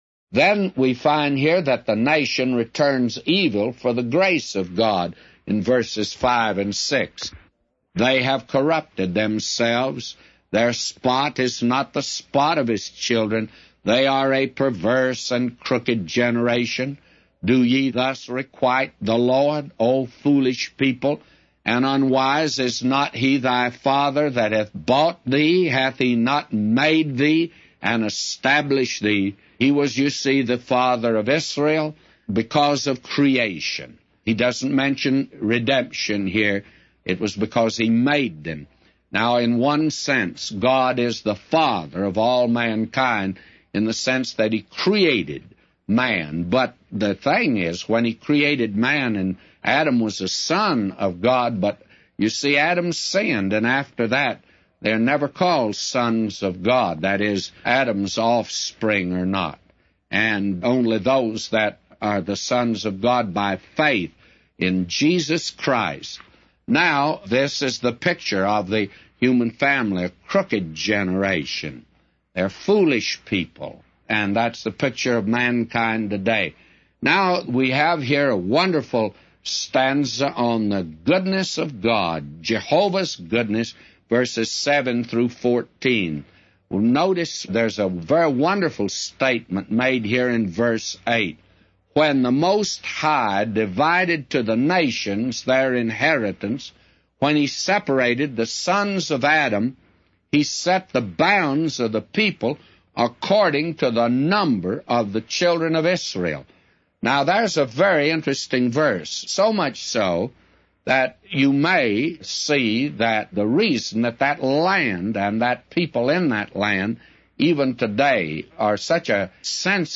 A Commentary By J Vernon MCgee For Deuteronomy 32:1-999